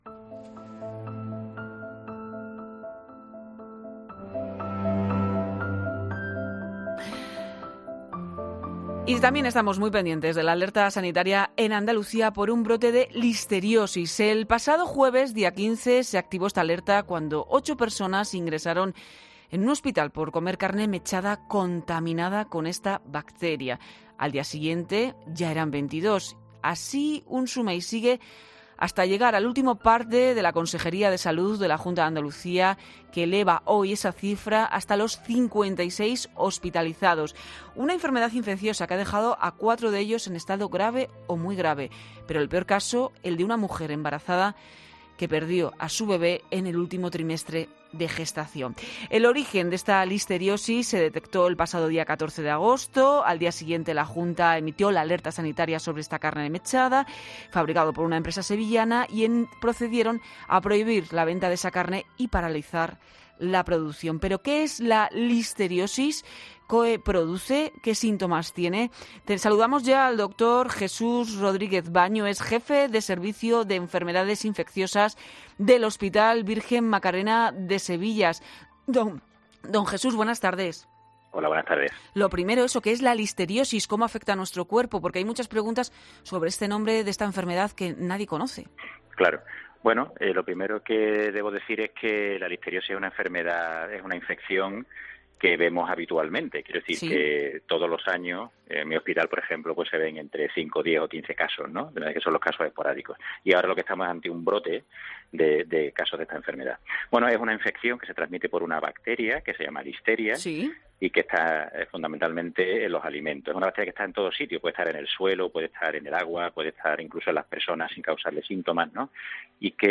Un doctor, sobre la listeriosis: "Estas bacterias se encuentran en todos lados"